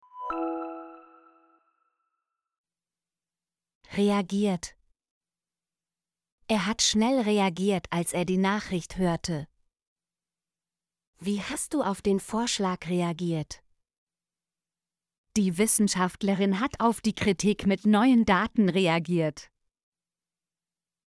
reagiert - Example Sentences & Pronunciation, German Frequency List